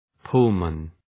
{‘pʋlmən}